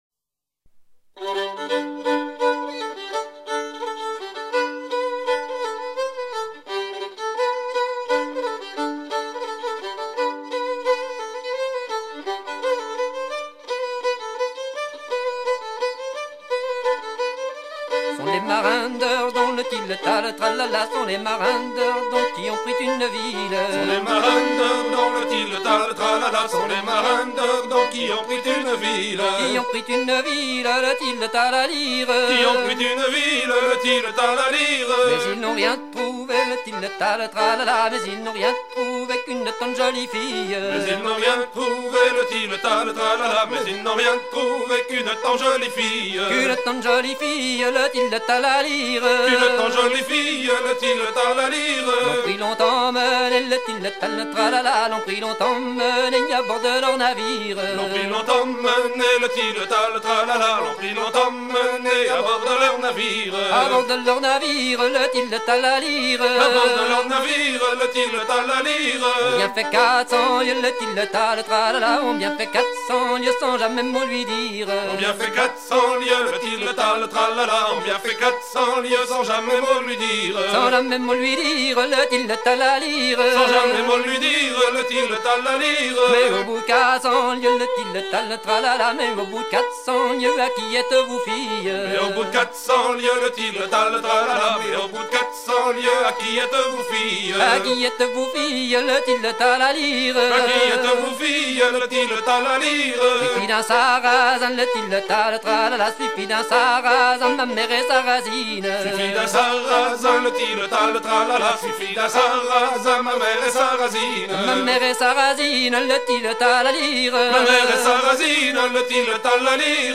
mélodie d'un air ridée à six temps
Genre laisse
Pièce musicale éditée